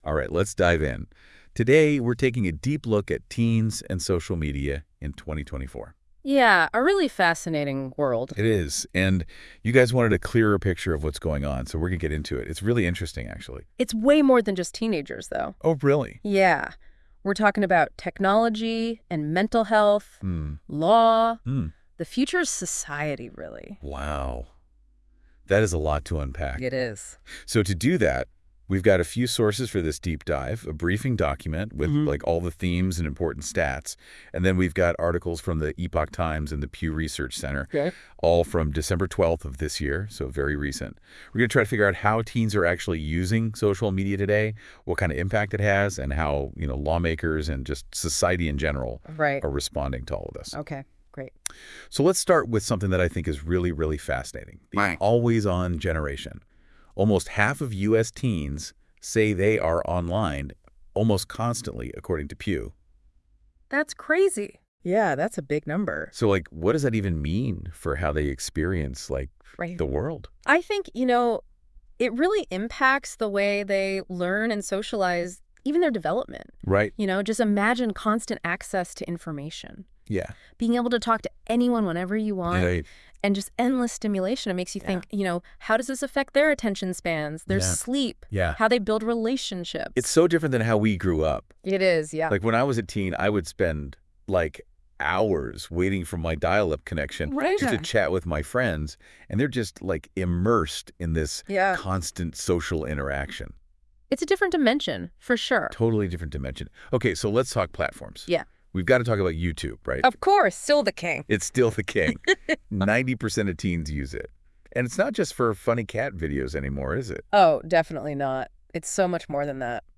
Listen to a convo on it all covering Pew Research and Epoch Times piece on Tenns online…. much more below